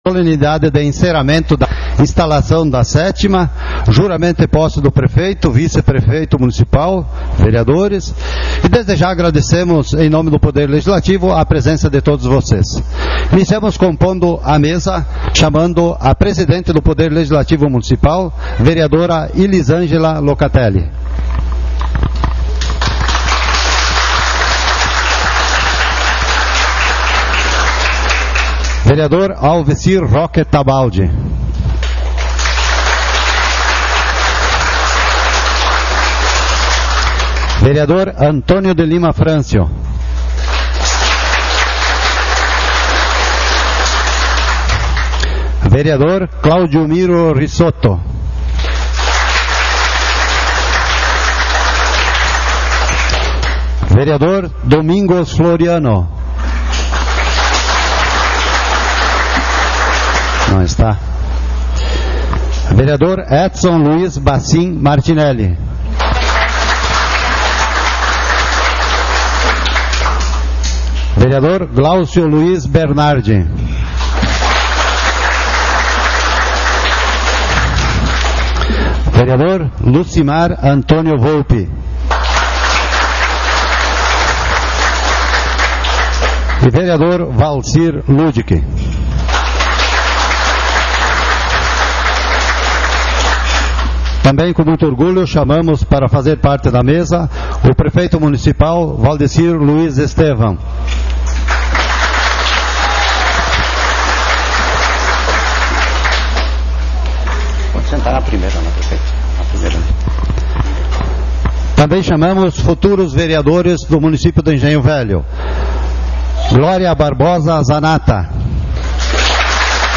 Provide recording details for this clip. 29.12.16 - Encerramento e Posse